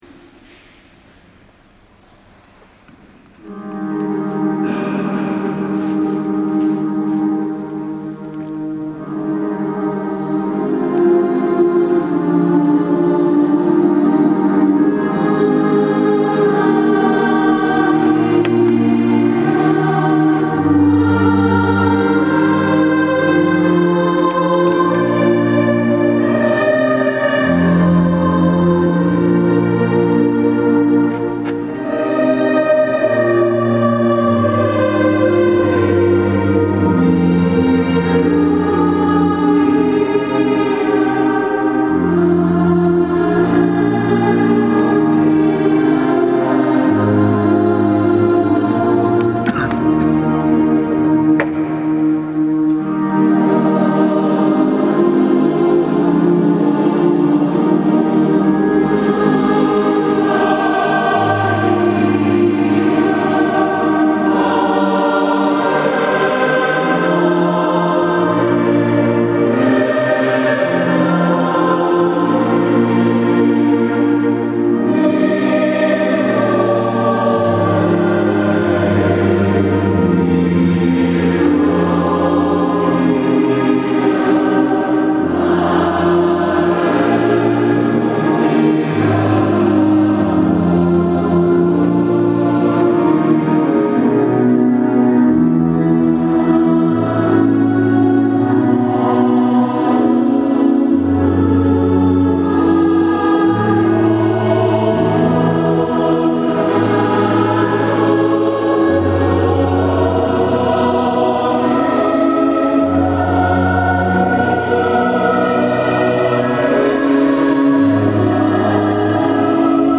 Sabato 07 ottobre 2017 la corale ha animato la S. Messa in occasione della festività della Madonna del Rosario.